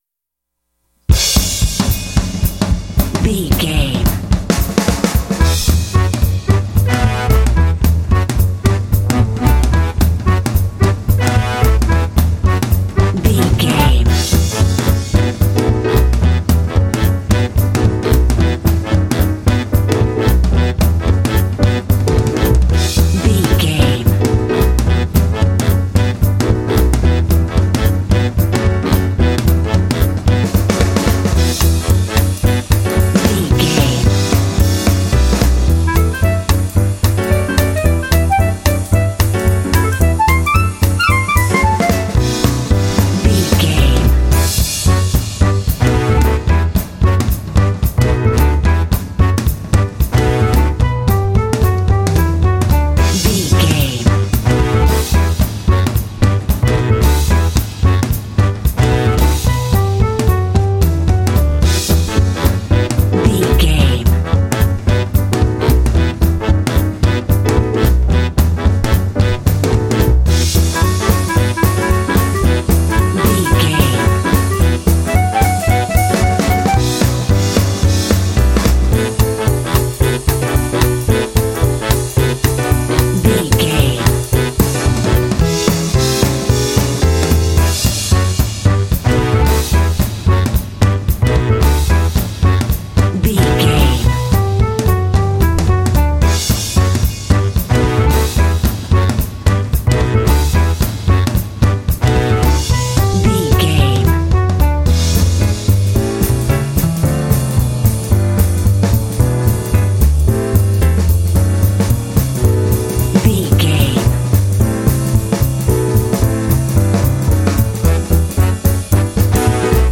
Uplifting
Aeolian/Minor
energetic
lively
cheerful/happy
drums
piano
brass
double bass
big band
jazz